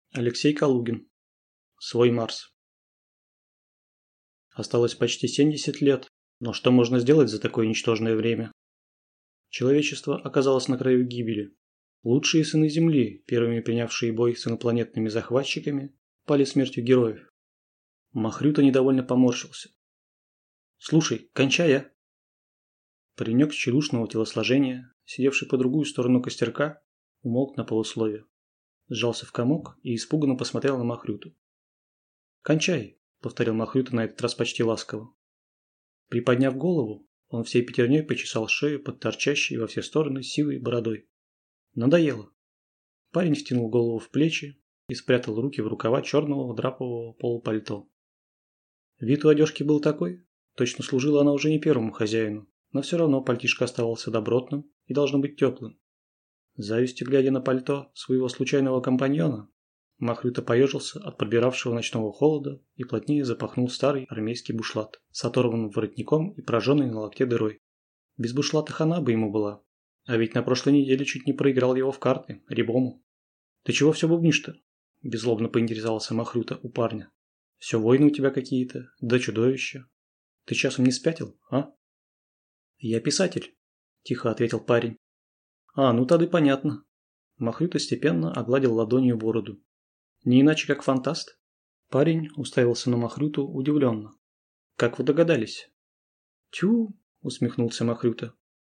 Аудиокнига Свой Марс | Библиотека аудиокниг
Прослушать и бесплатно скачать фрагмент аудиокниги